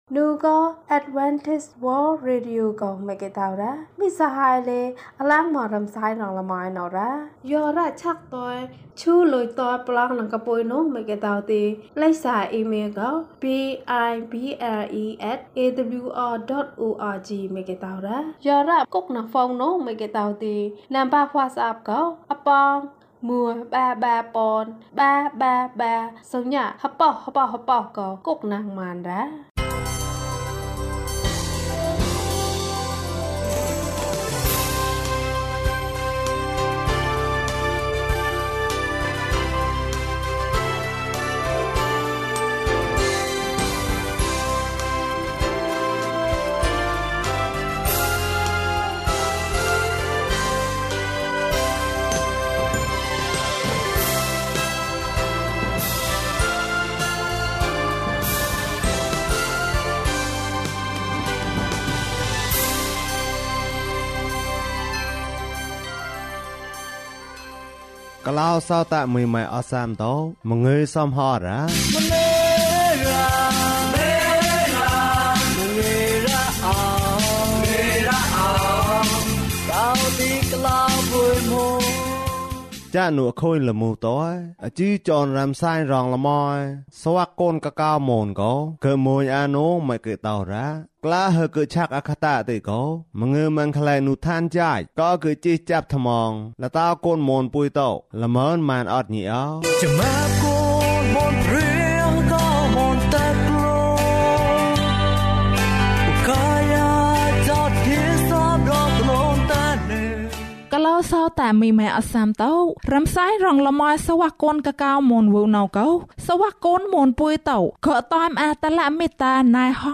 အသက်ရှင်သောဘုရားသခင်။ ကျန်းမာခြင်းအကြောင်းအရာ။ ဓမ္မသီချင်း။ တရားဒေသနာ။